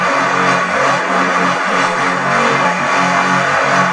saw_loop2.wav